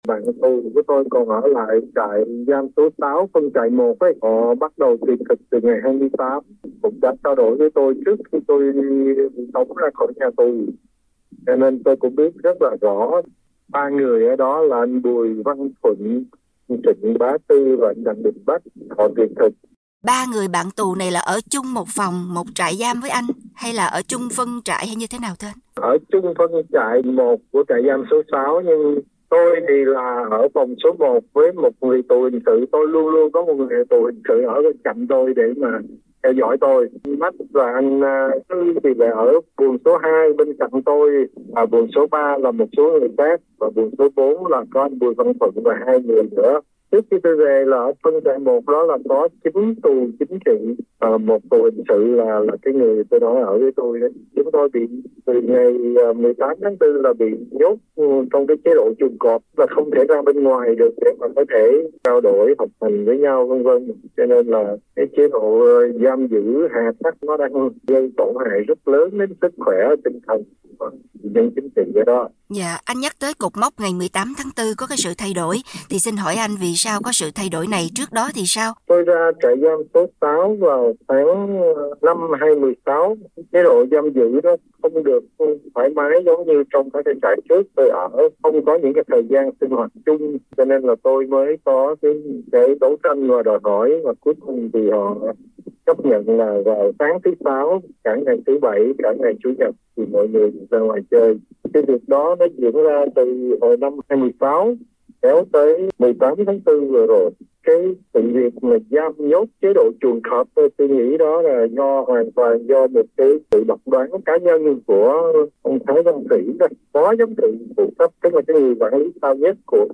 trò chuyện với Trần Huỳnh Duy Thức, một trong những tù nhân chính trị nổi tiếng nhất vừa được trả tự do, về những gì đã trải qua trong trại giam hơn 15 năm qua. Anh mở đầu câu chuyện bằng cuộc tuyệt thực đang diễn ra của ba người bạn tù trong trại giam số 6 ở huyện Thanh Chương, tỉnh Nghệ An.